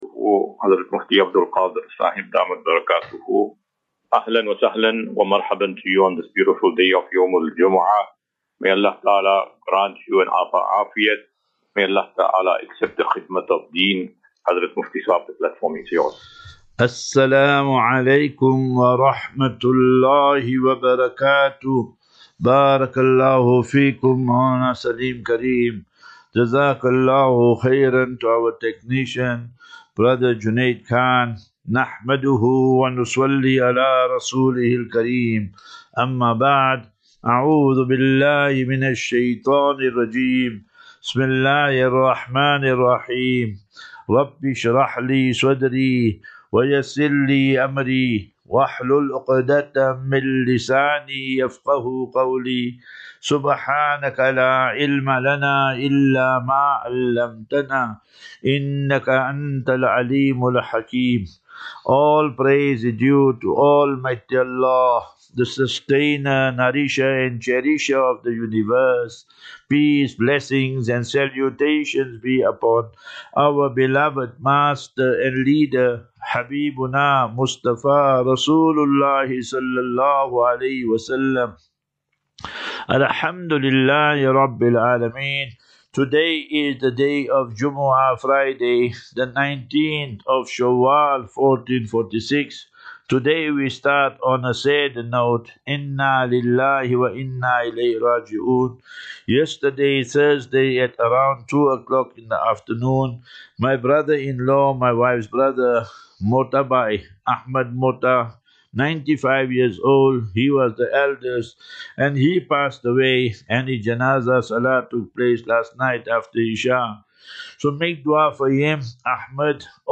Assafinatu - Illal - Jannah. QnA
Daily Naseeha.